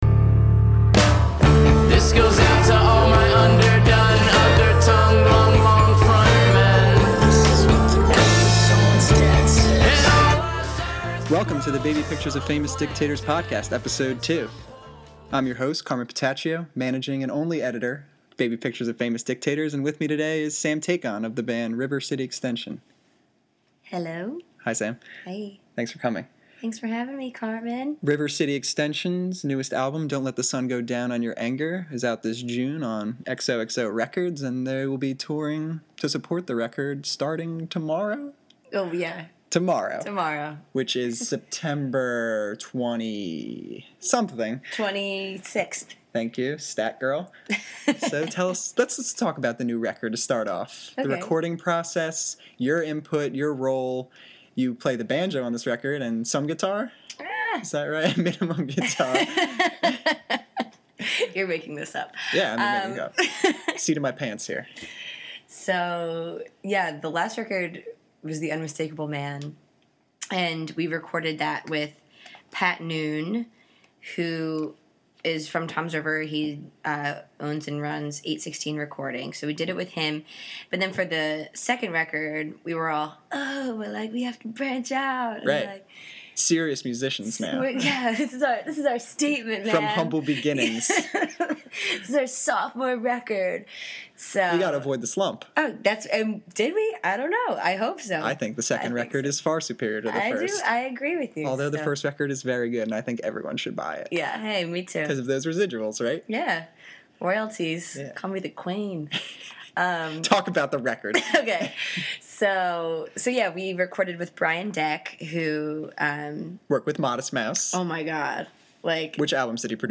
dusts off the old six-string for an impromptu performance